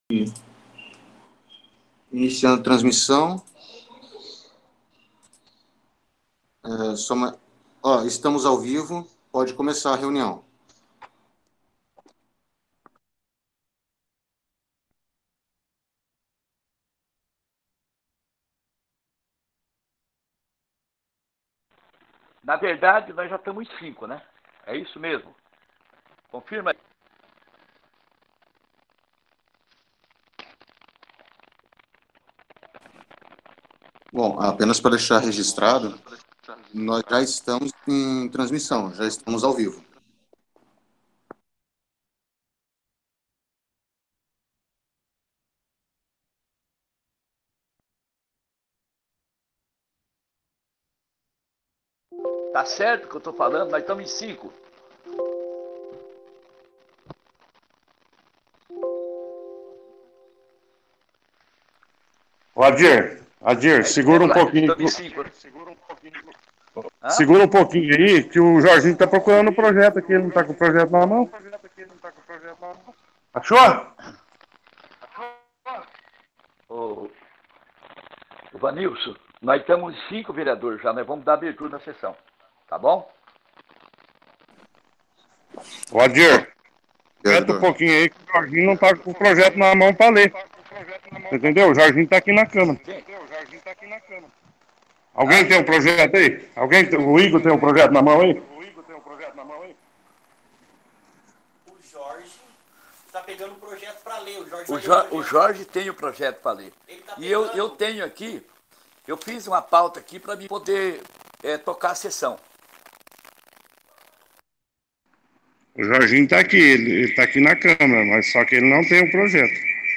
13ª Reunião Extrardinária 12-09-20.mp3